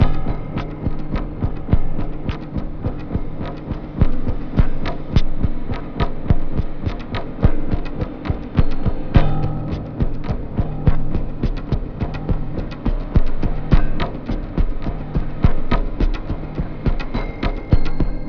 Downtempo 04.wav